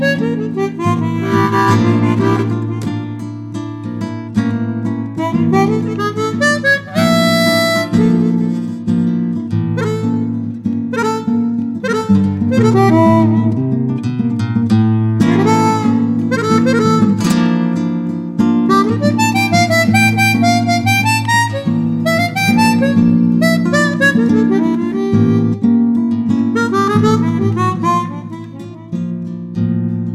voix
harmonica
guitare